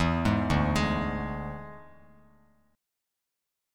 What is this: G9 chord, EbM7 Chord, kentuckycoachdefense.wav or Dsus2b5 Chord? Dsus2b5 Chord